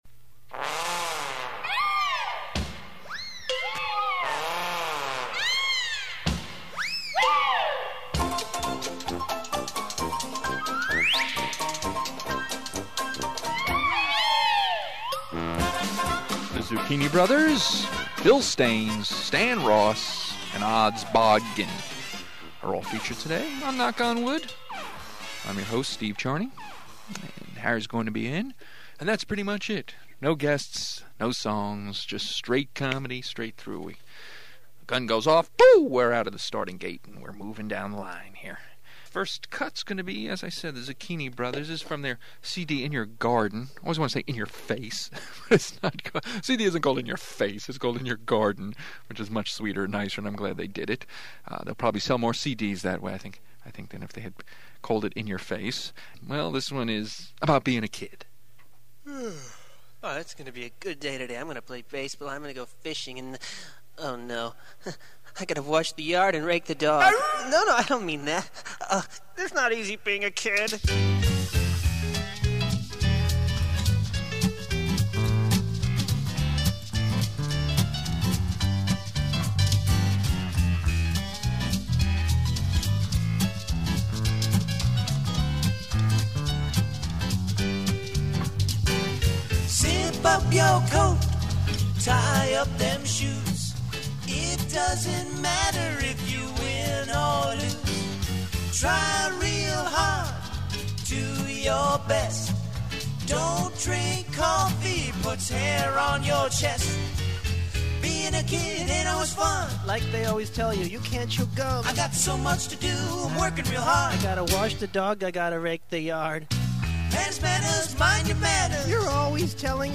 Knock On Wood Comedy Show